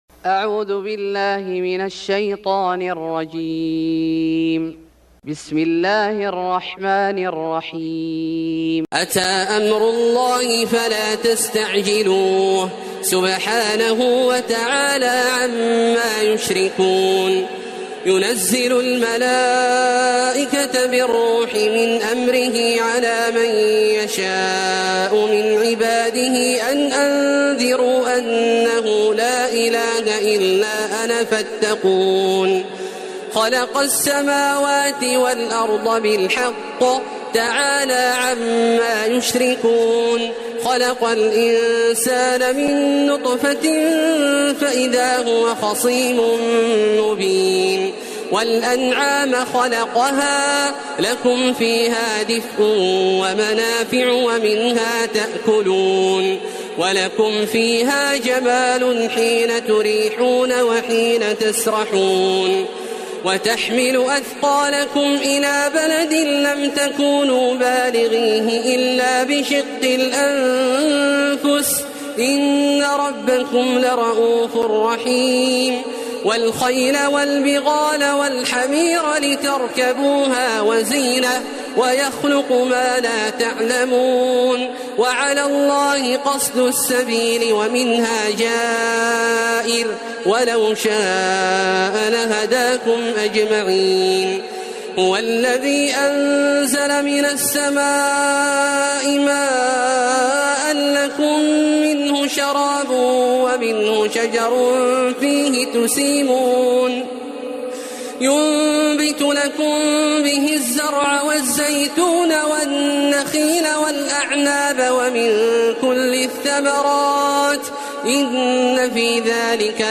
سورة النحل Surat An-Nahl > مصحف الشيخ عبدالله الجهني من الحرم المكي > المصحف - تلاوات الحرمين